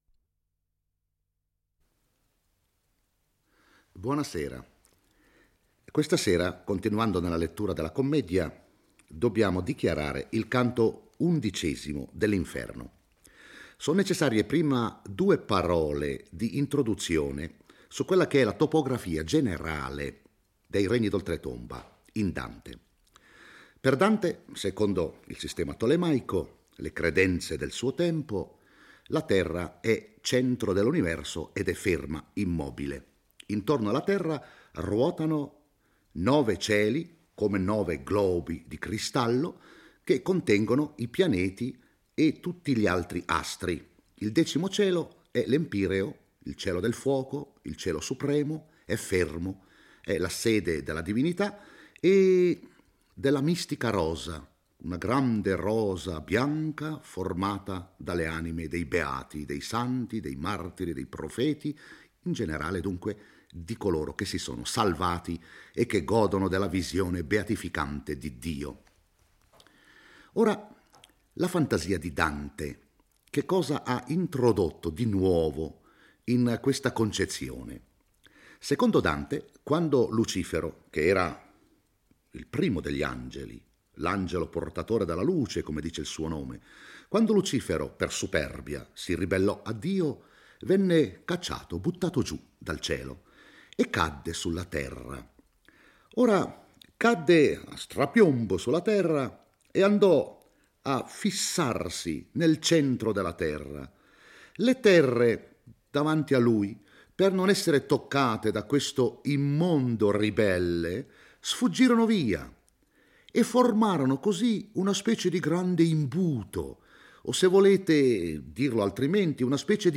legge e commenta il canto XI dell'Inferno.